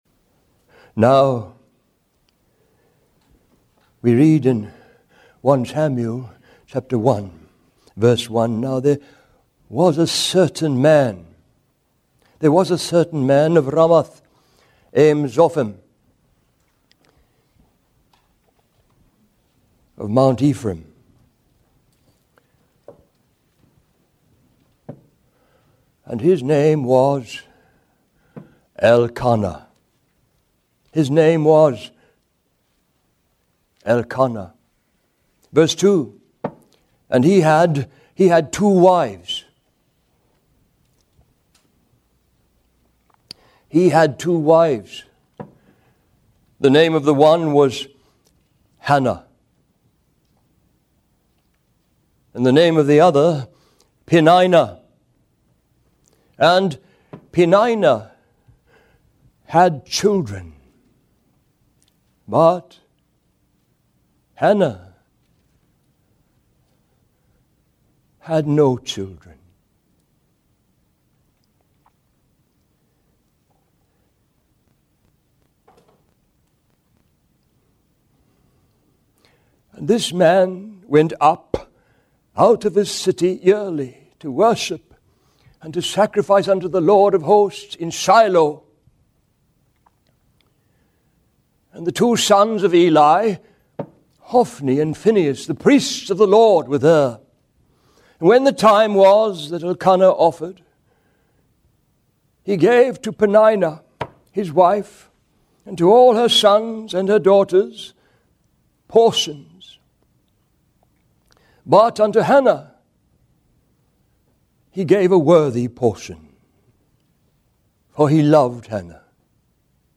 This sermon delves into the story of Elkanah, Hannah, and Samuel from 1 Samuel, highlighting the complexities of faith, parenting, and the consequences of choices. It emphasizes the importance of not giving up on children, the impact of godly upbringing, the danger of not truly knowing the Lord despite religious upbringing, and the urgency of responding to God's call before it's too late.